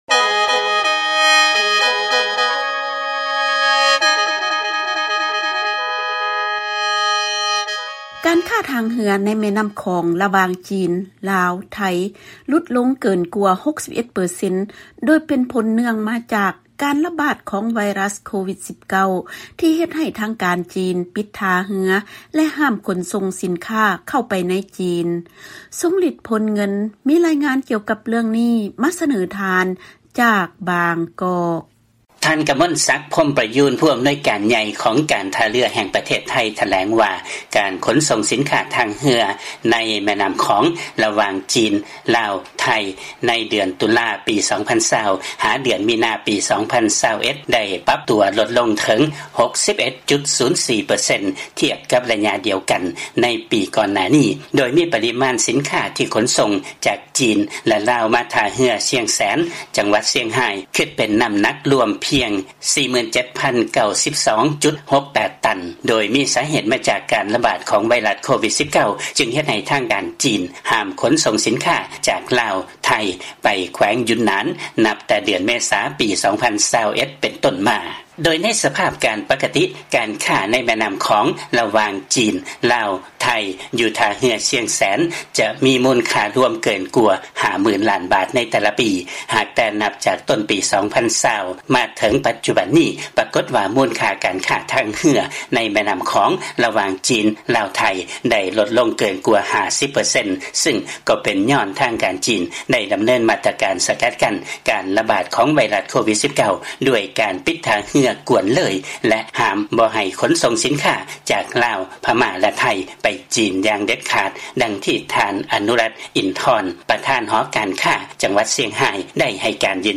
ເຊີນຟັງລາຍງານ ການຄ້າທາງເຮືອ ລະຫວ່າງ ຈີນ-ລາວ-ໄທ ຫລຸດລົງເກີນກວ່າ 61 ເປີເຊັນ ເປັນຜົນມາຈາກການລະບາດຂອງ ໂຄວິດ-19